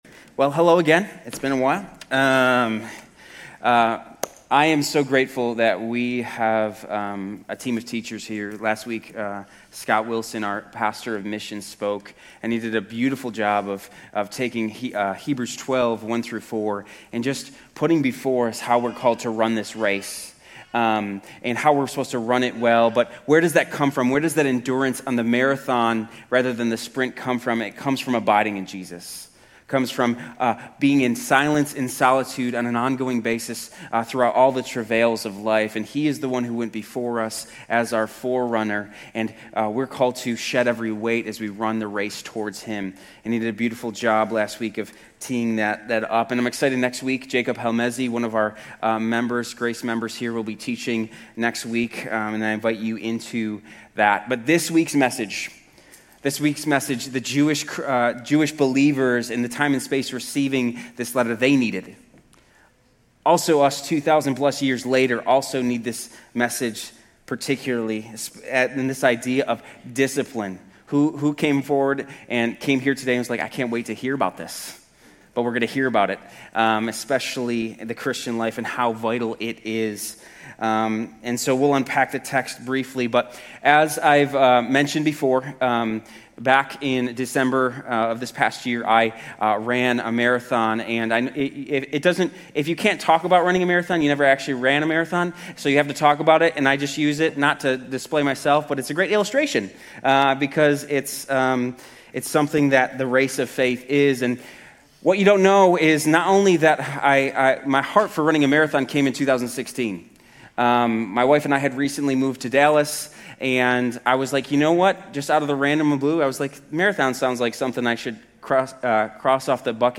Grace Community Church University Blvd Campus Sermons 3_22 University Blvd Campus Mar 23 2026 | 00:31:47 1x 00:00 / 00:31:47 Subscribe Share RSS Feed Share Link Embed